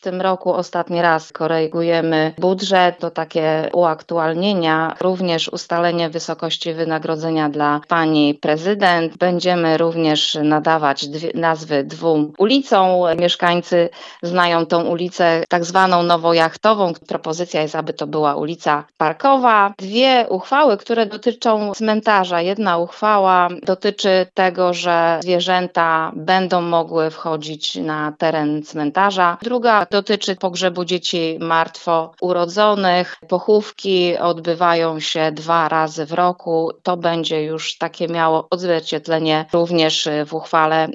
O tym, nad jakimi uchwałami pochylą się podczas obrad, mówi wiceprzewodnicząca rady Elżbieta Jabłońska.